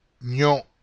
The letter Ñ, is one of “the new letters” for the English-Speaking people and you can try the phonetic association, which is very nasalized,  with sound “ni” in Onion.